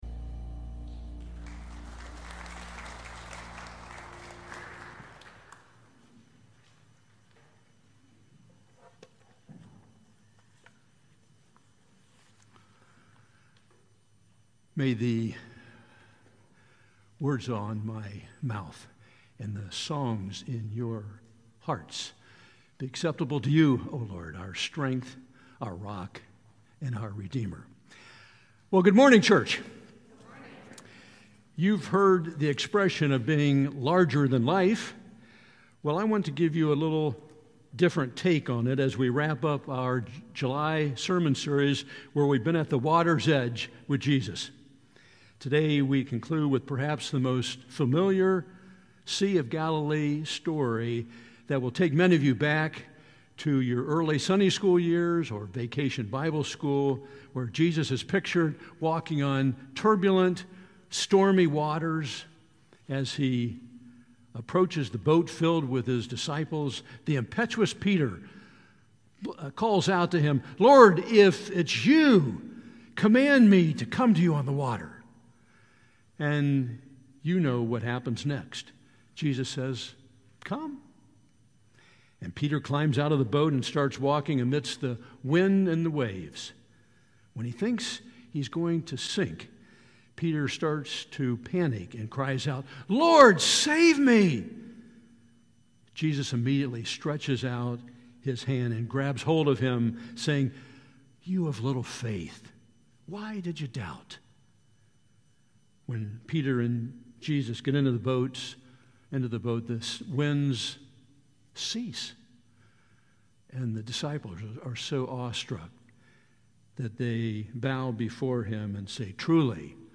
July 28, 2024 Sermon